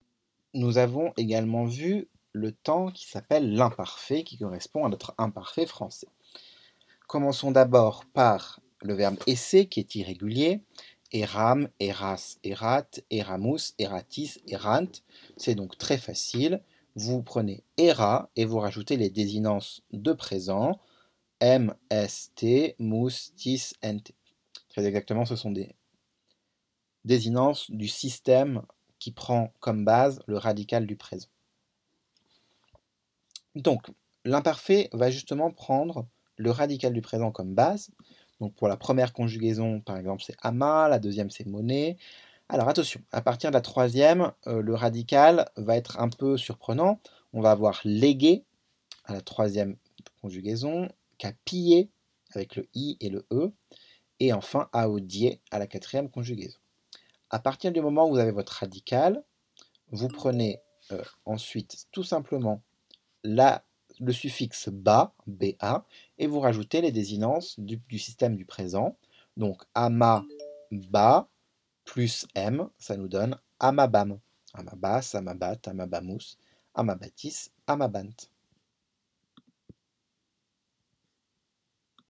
Mémos vocaux pour le latin
Pardon par avance pour les petites hésitations, erreurs de langues et autres zozotements ! =)